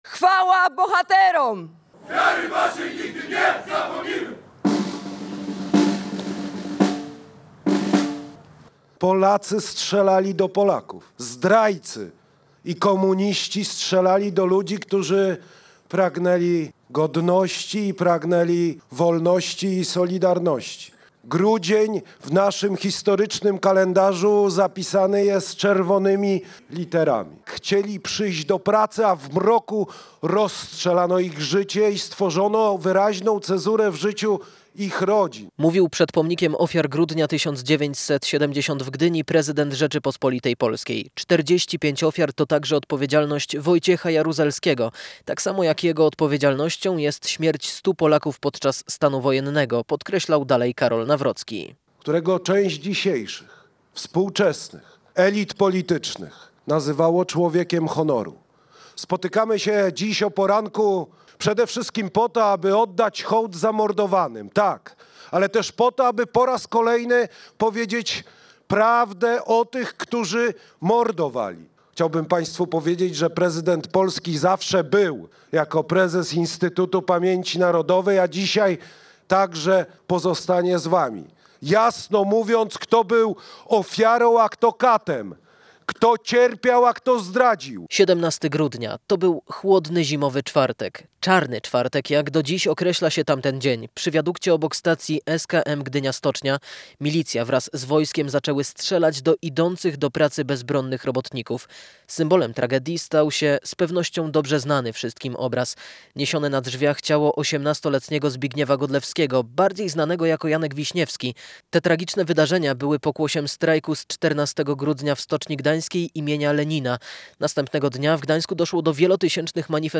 Przed pomnikiem Ofiar Grudnia 1970 w Gdyni zakończyły się uroczystości upamiętniające czarny czwartek.
– Spotykamy się, aby oddać hołd zamordowanym, ale powiedzieć też prawdę o tych, którzy mordowali – podkreślił w środę w Gdyni prezydent Karol Nawrocki podczas obchodów 55. rocznicy czarnego czwartku w grudniu 1970 r.